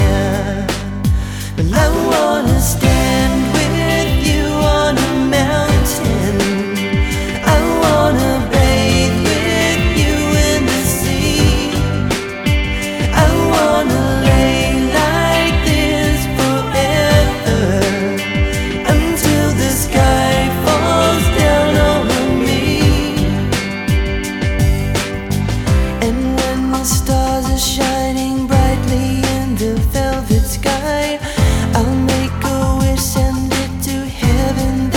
Жанр: Поп музыка / Рок / Танцевальные / Альтернатива